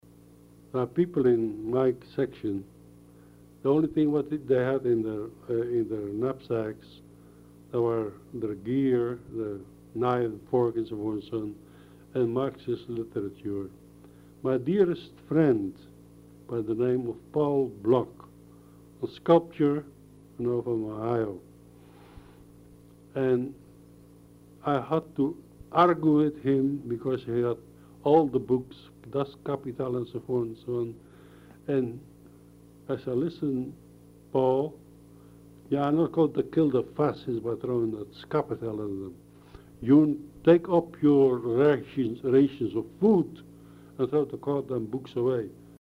on a set of crackling, hissing reel-to-reel tapes that no one had played in years.
an old voice speaking English with an unmistakable Dutch accent.